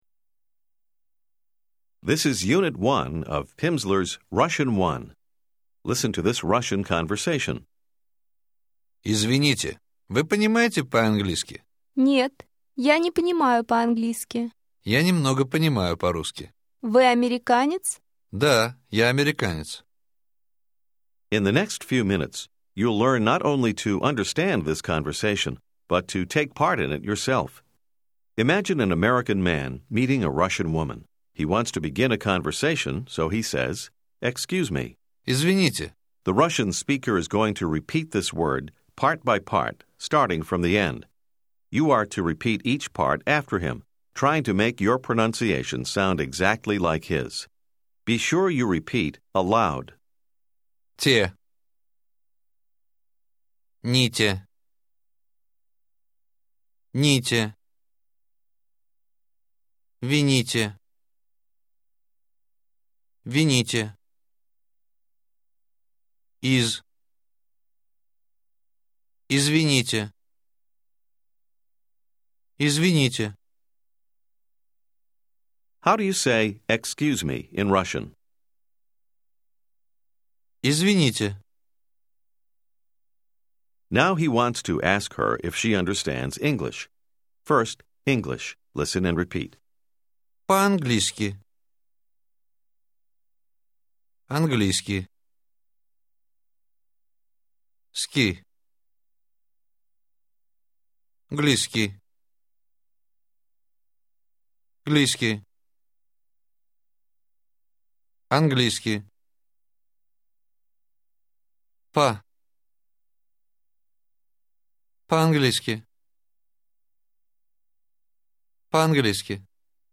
Russian Phase 1, Unit 1 contains 30 minutes of spoken language practice, with an introductory conversation, and isolated vocabulary and structures.